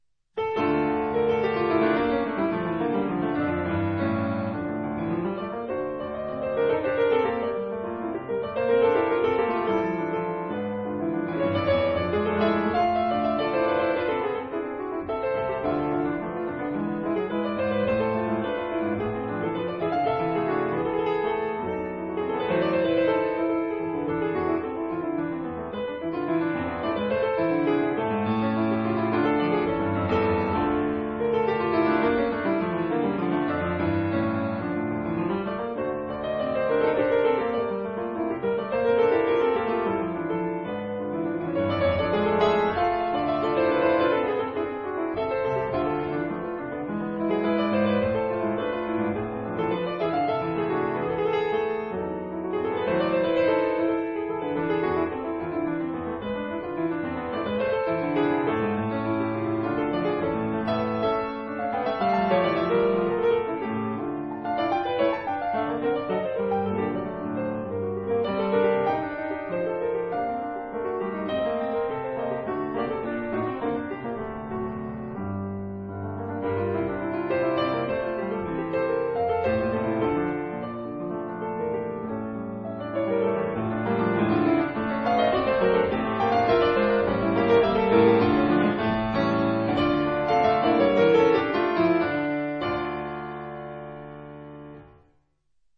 如果不熟悉曲目，那一定會覺得這是鋼琴曲。